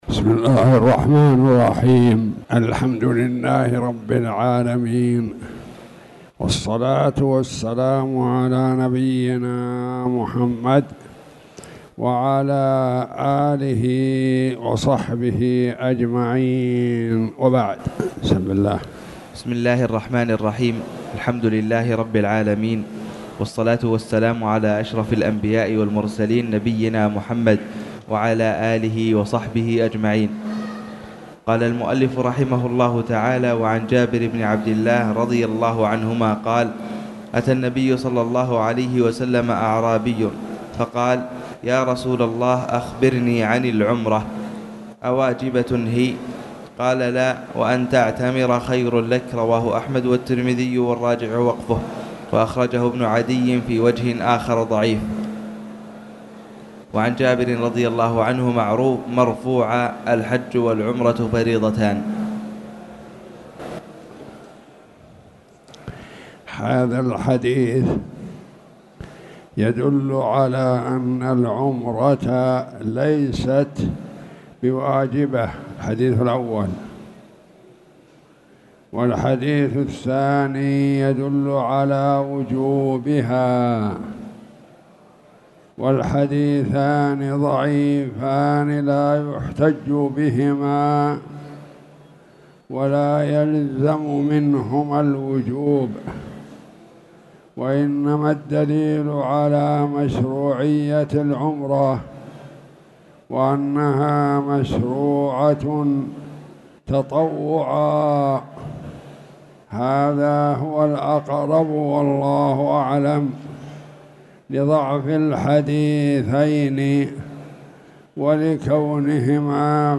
تاريخ النشر ٦ صفر ١٤٣٨ هـ المكان: المسجد الحرام الشيخ